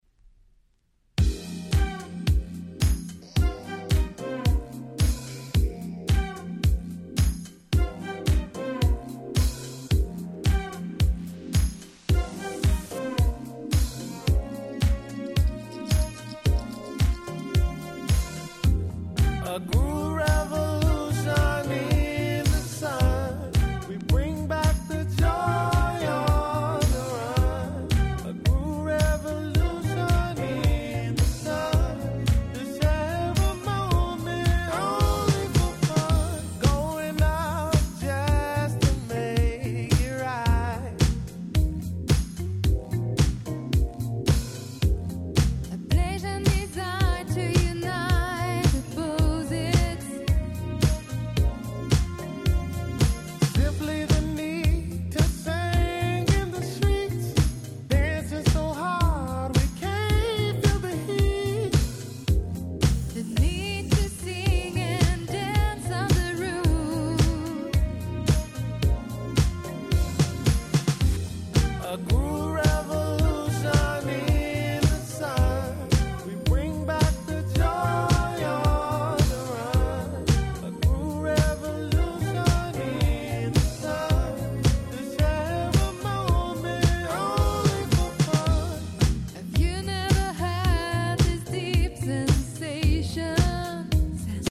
聴いていてワクワクしてしまう様な素敵なBoogieやModern Soulがてんこ盛りです！！